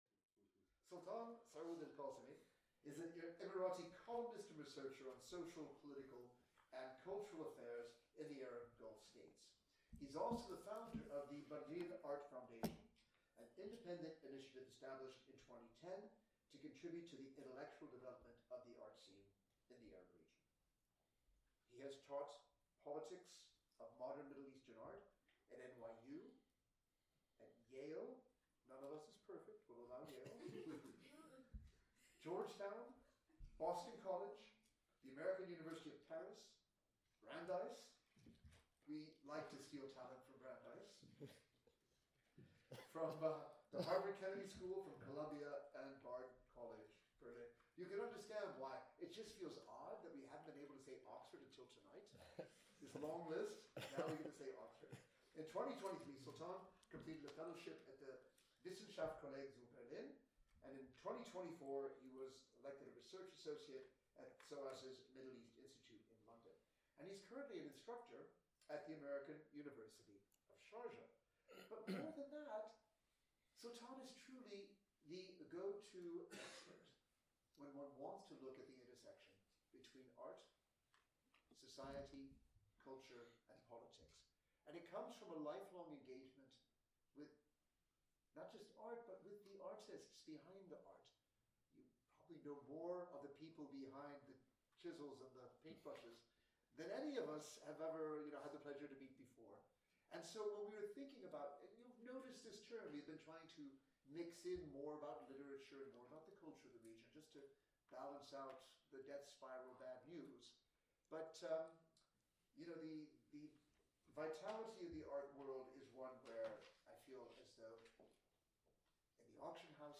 This seminar was delivered at the Middle East Centre on Thursday 20 November by Sultan Sooud Al-Qassemi and was chaired by Professor Eugene Rogan, St Antony’s College.